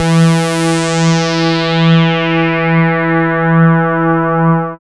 75.06 BASS.wav